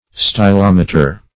Search Result for " stylometer" : The Collaborative International Dictionary of English v.0.48: Stylometer \Sty*lom"e*ter\, n. [Gr.